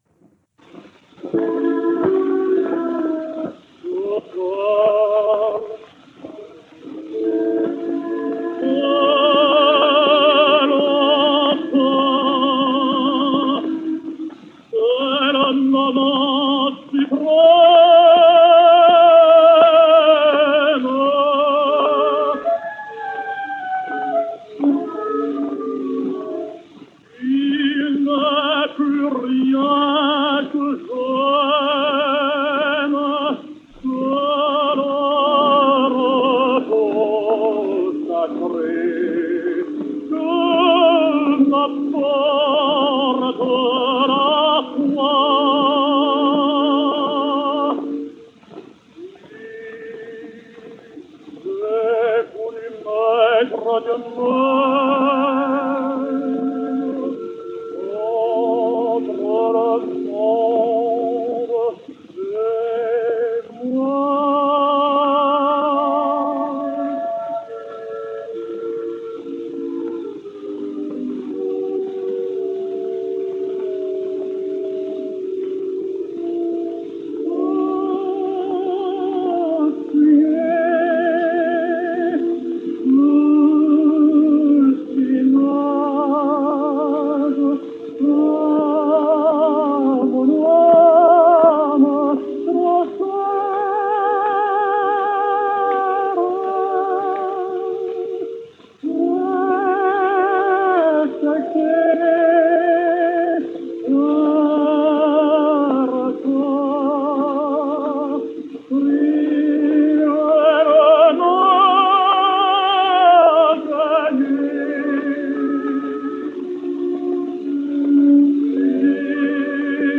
French Tenor.